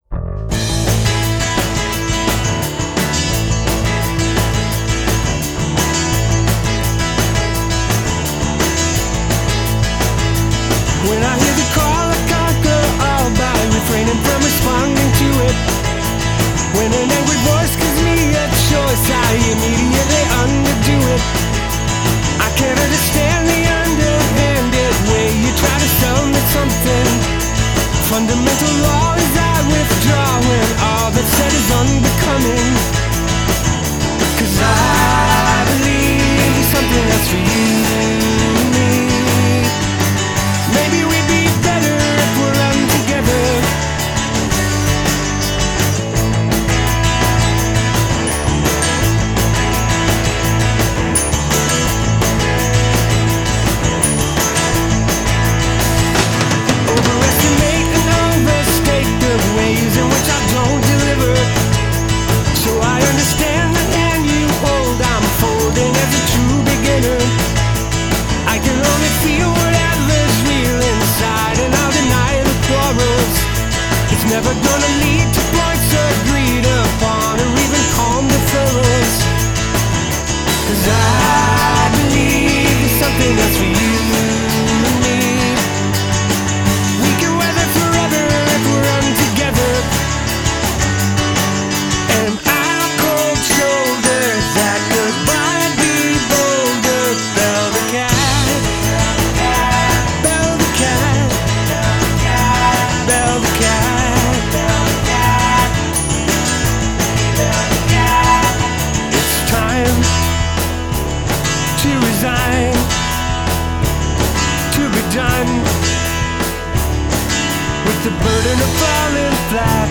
Marshall Crenshaw-ish